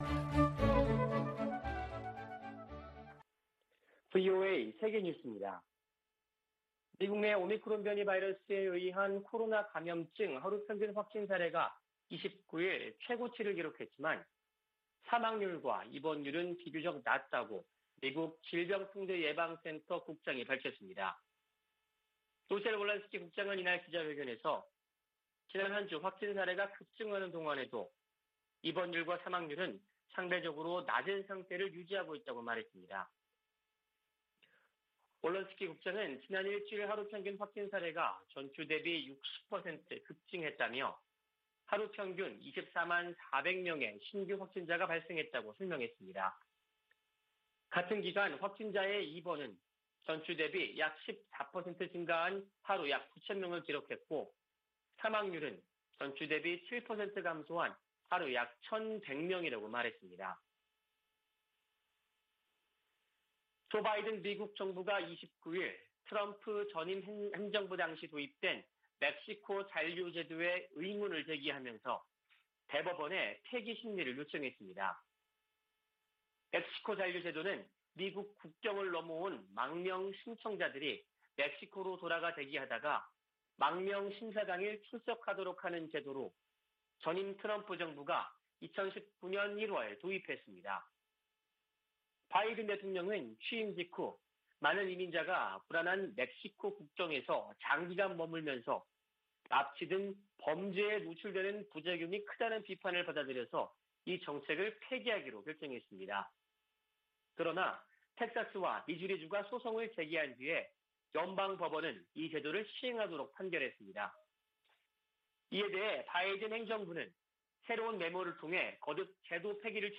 VOA 한국어 아침 뉴스 프로그램 '워싱턴 뉴스 광장' 2021년 12월 31일 방송입니다. 미 국방부는 다양한 안보 문제에 대해 한국 동맹군과 정기적으로 합동 계획을 수립하고 있다고 밝혔습니다. 올해 김정은 북한 국무위원장은 이례적으로 군사훈련 관련 공개 활동을 하지 않은 것으로 나타났습니다. 북한이 지난해 1월부터 코로나바이러스 유입을 막겠다며 국경을 전면 봉쇄한 가운데, 아직 재개방 조짐은 보이지 않고 있습니다.